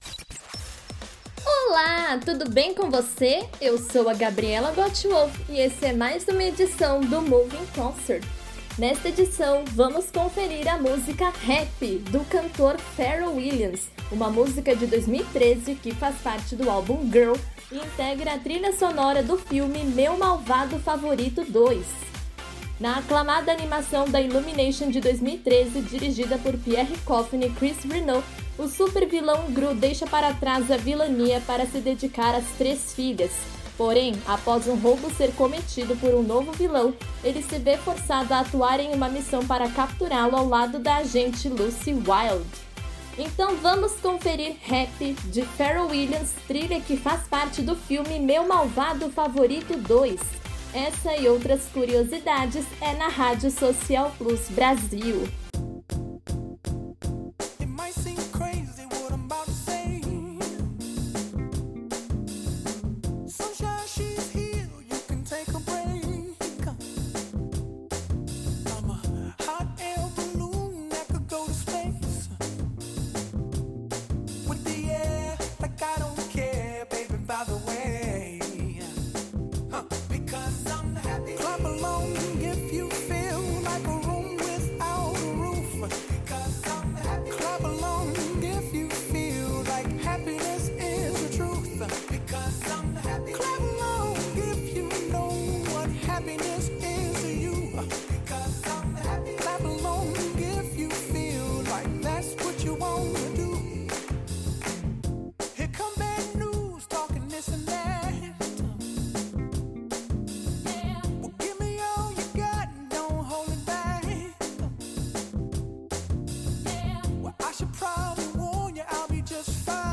com seu ritmo contagiante e letra otimista